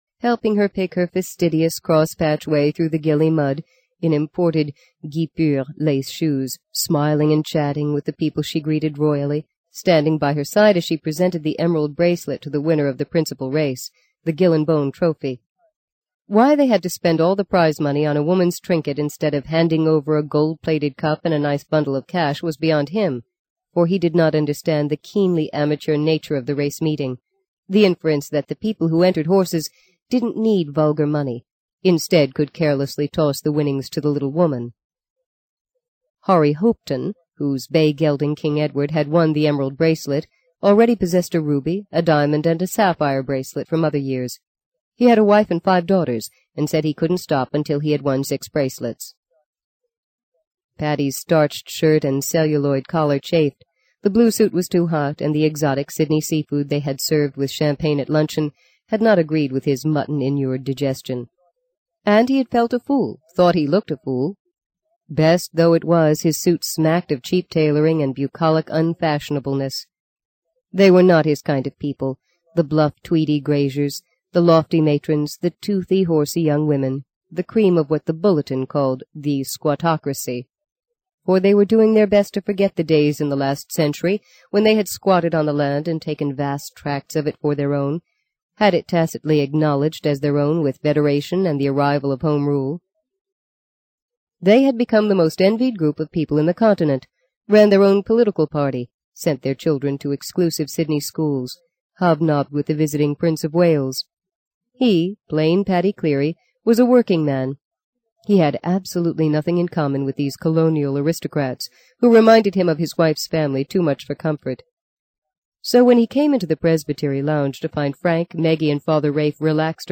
在线英语听力室【荆棘鸟】第五章 08的听力文件下载,荆棘鸟—双语有声读物—听力教程—英语听力—在线英语听力室